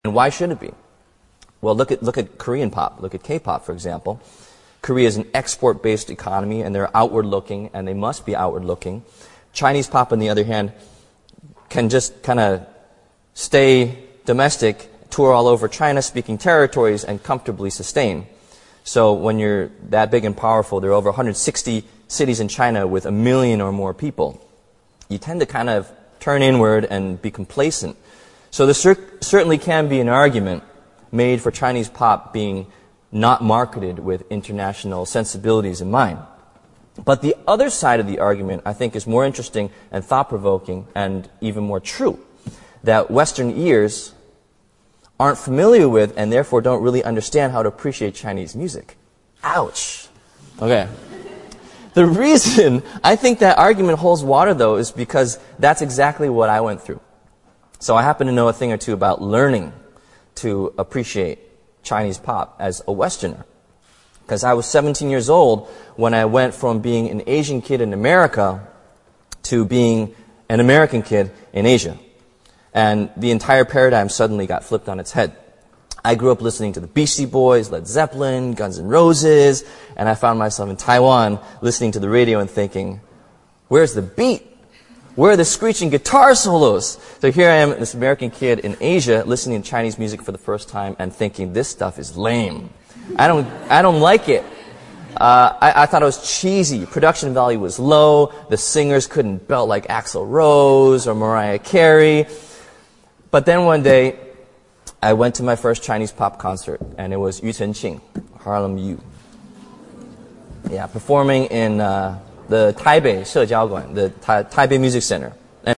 王力宏牛津大学演讲 第9期 听力文件下载—在线英语听力室
在线英语听力室王力宏牛津大学演讲 第9期的听力文件下载,哈佛牛津名人名校演讲包含中英字幕音频MP3文件，里面的英语演讲，发音地道，慷慨激昂，名人的效应就是激励他人努力取得成功。